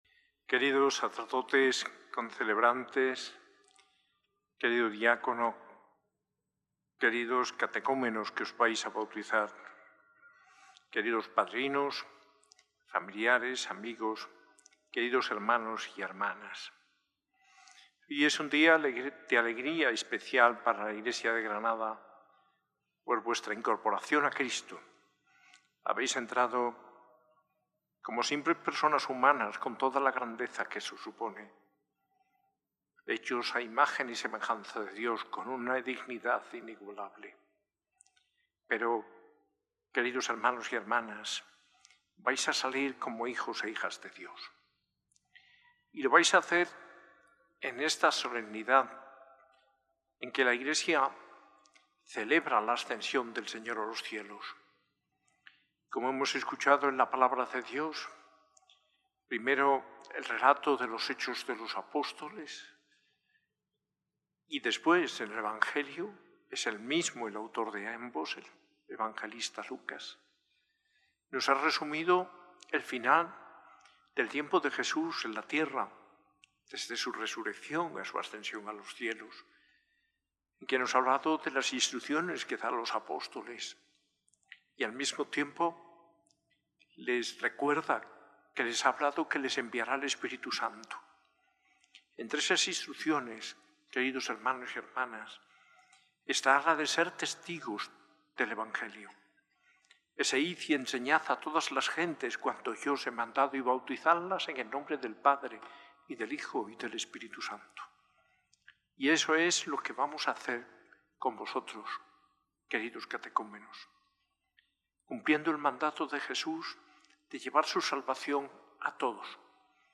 Homilía en la Eucaristía del VII Domingo del Tiempo Pascual de manos del arzobispo Mons. José María Gil Tamayo, celebrada en la S.A.I Catedral el 1 de junio de 2025.